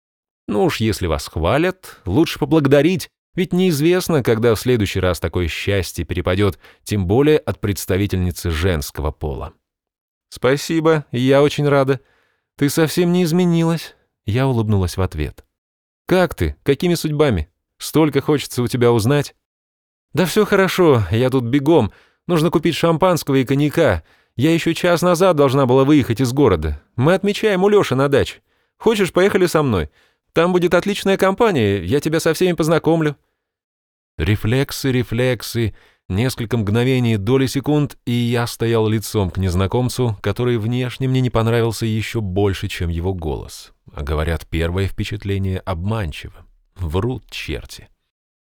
Запись с дикторами Rideró